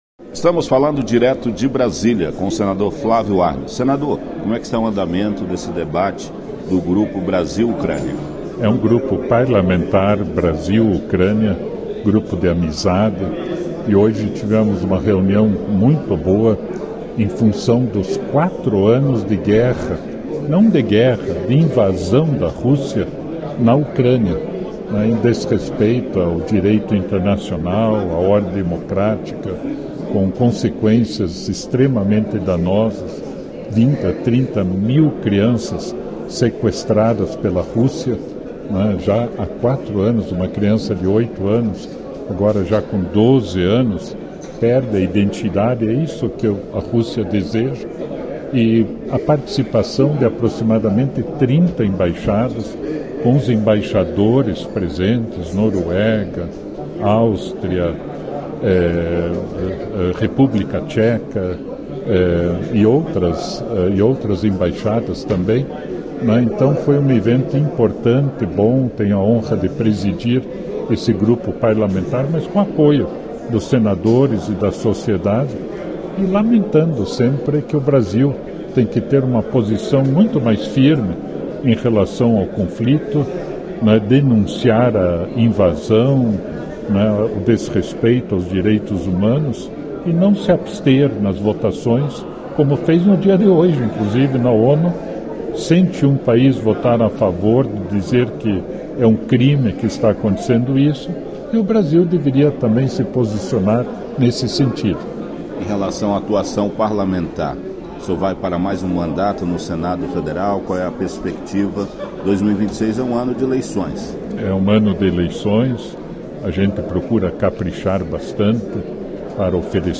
falou com o senador